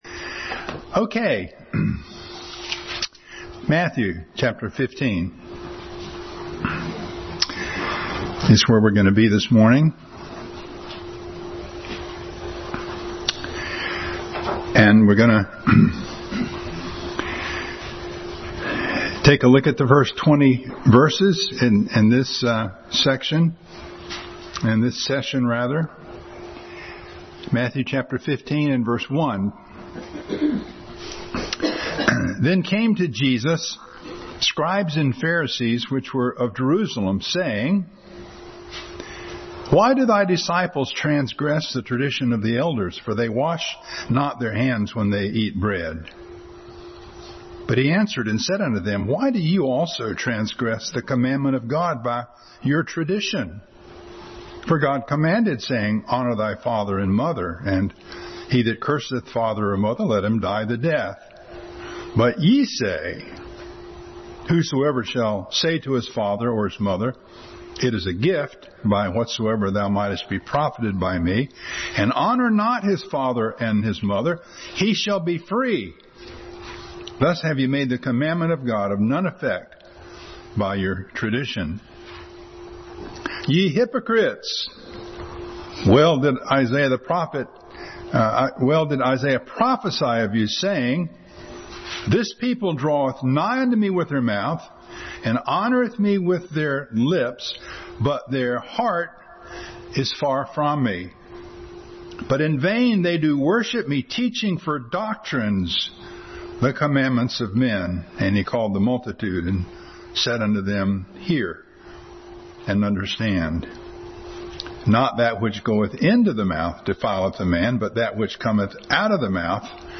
Matthew 15:1-20 Passage: Matthew 15:1-20, 12:34, James 1:15, Psalm 24:3-4, 15:1-5 Service Type: Sunday School